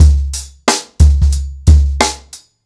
• 90 Bpm Drum Beat C# Key.wav
Free breakbeat - kick tuned to the C# note. Loudest frequency: 1231Hz
90-bpm-drum-beat-c-sharp-key-pGj.wav